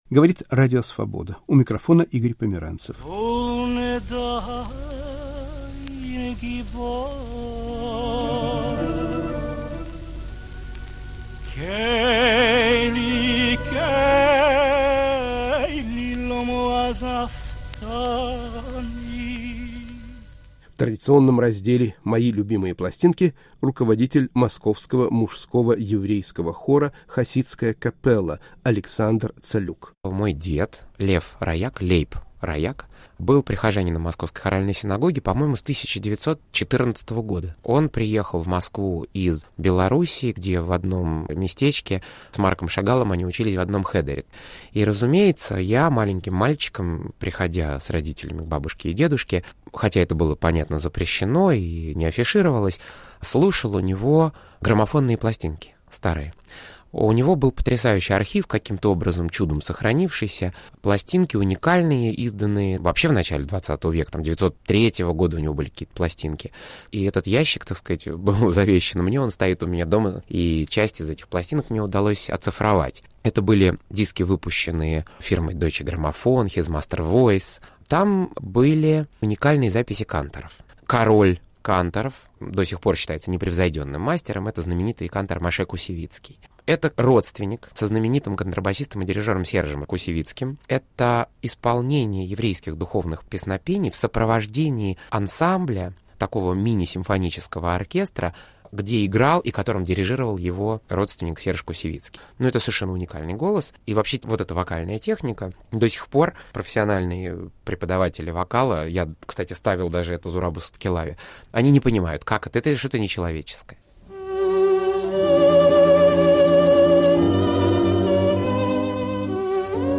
Синагогальные канторы